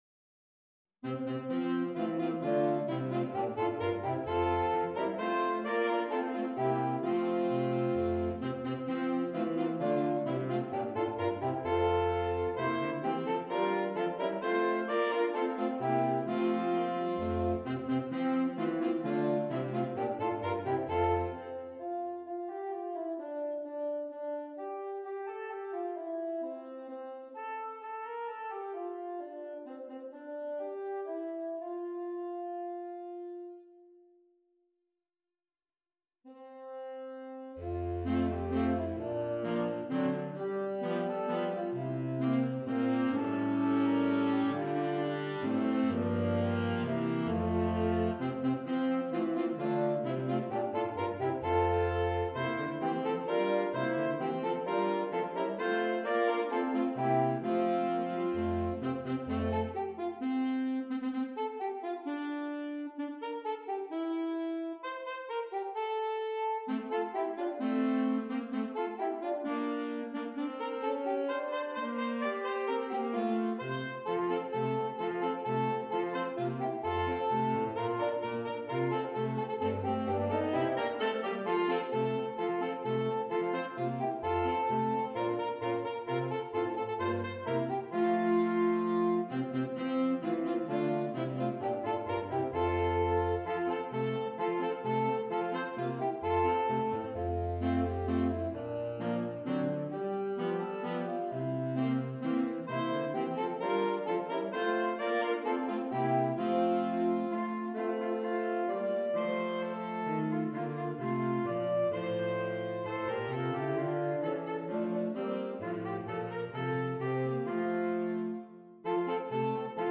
Saxophone Quartet (SATB or AATB)
Traditional